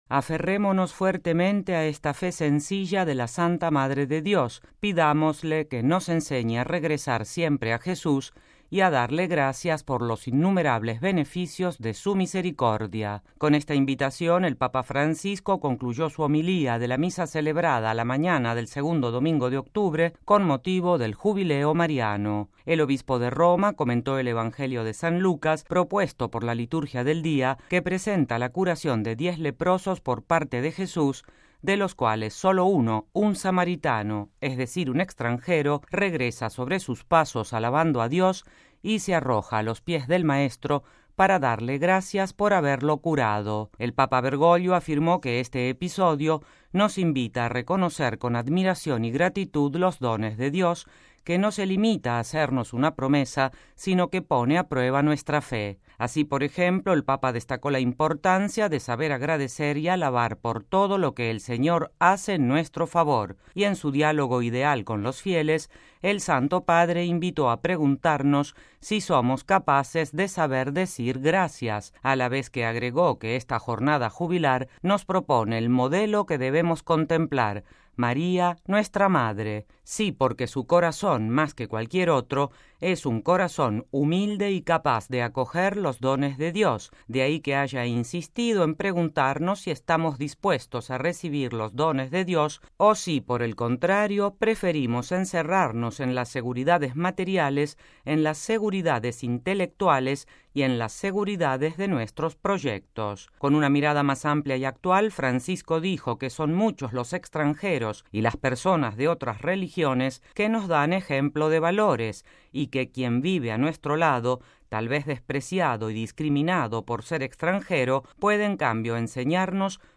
Con esta invitación el Papa Francisco concluyó su homilía de la Misa celebrada la mañana del segundo domingo de octubre en el atrio de la Basílica Vaticana, con motivo del Jubileo Mariano.